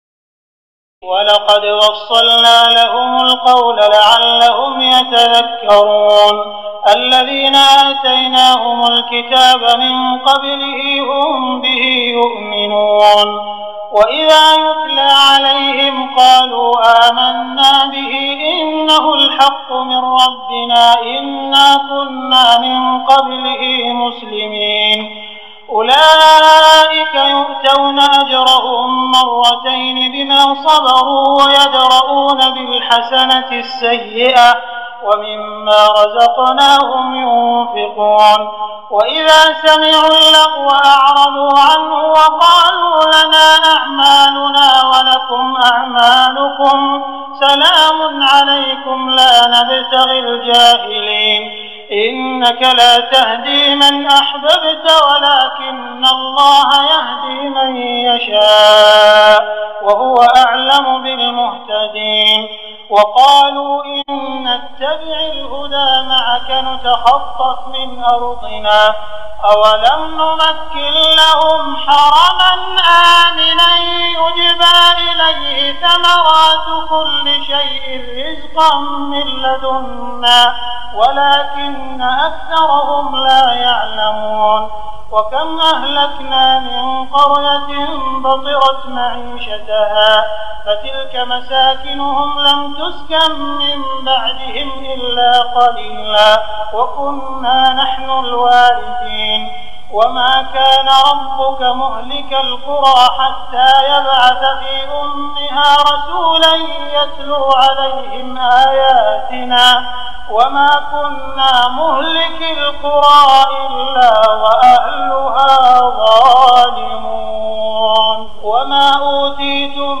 تراويح الليلة التاسعة عشر رمضان 1419هـ من سورتي القصص (51-88) والعنكبوت (1-45) Taraweeh 19 st night Ramadan 1419H from Surah Al-Qasas and Al-Ankaboot > تراويح الحرم المكي عام 1419 🕋 > التراويح - تلاوات الحرمين